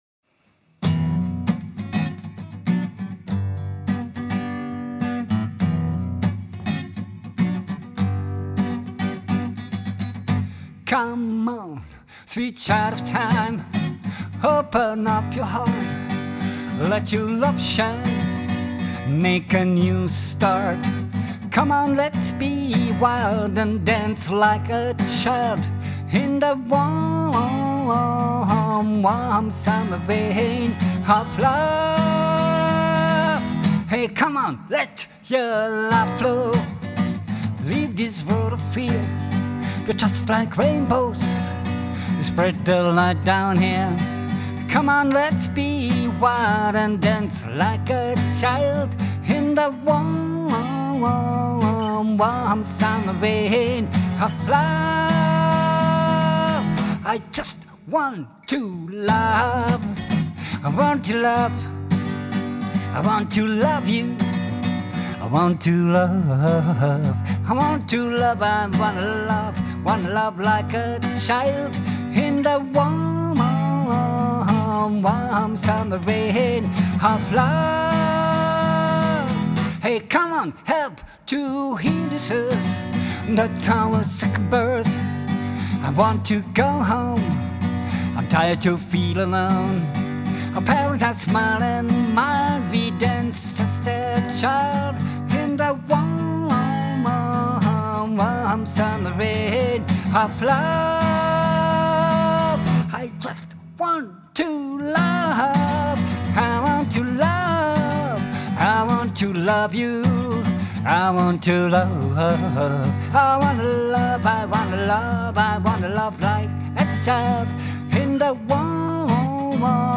Live eingespielt, Gitarre und Gesang gestimmt in 432kHz.